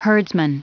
Prononciation du mot herdsman en anglais (fichier audio)
Prononciation du mot : herdsman